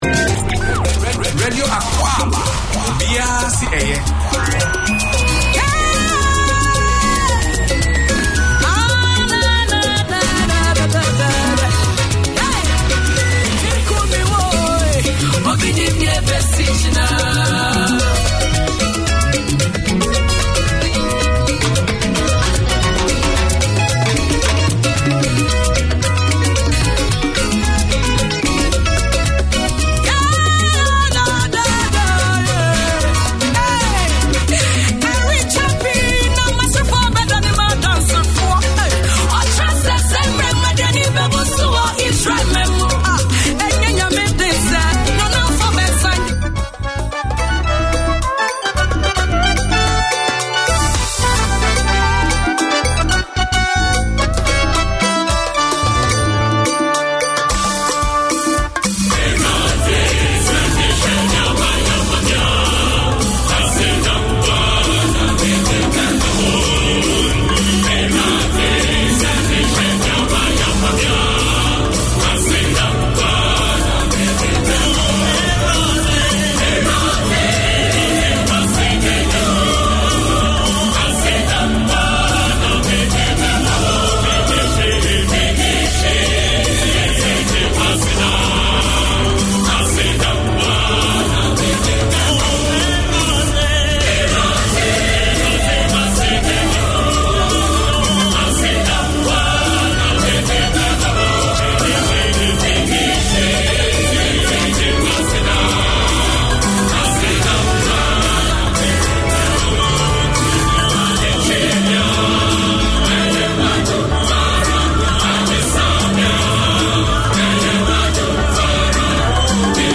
Radio Akwaaba is a programme targeted to the Ghanaian Community and all those who are interested in Ghana or African culture. It brings news, current affairs and sports reporting from Ghana along with music and entertainment.
Radio Akwaaba 8:30pm SATURDAY Community magazine Language: English Ghanaian Radio Akwaaba is a programme targeted to the Ghanaian Community and all those who are interested in Ghana or African culture.